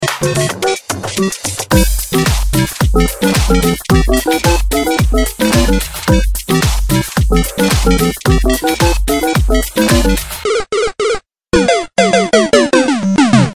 Trò Chơi Điện Tử Cực Hot Tik Tok
Remix